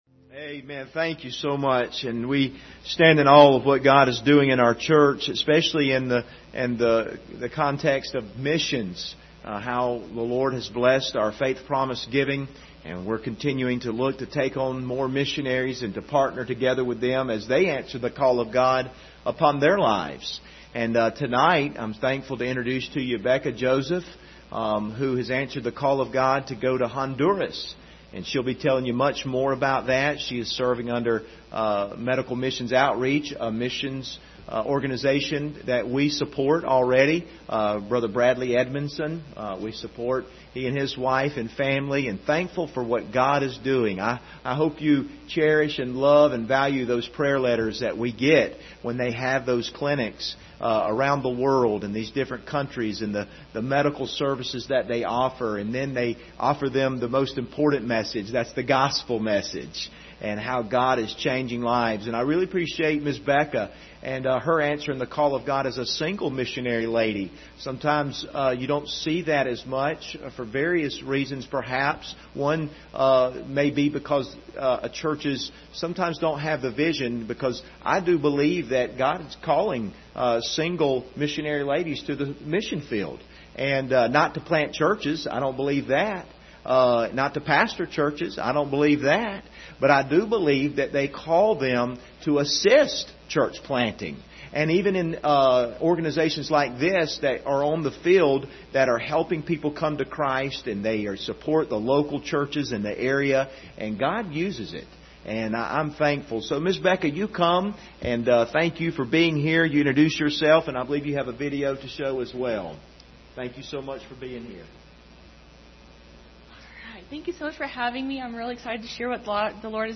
Service Type: Sunday Evening Topics: missions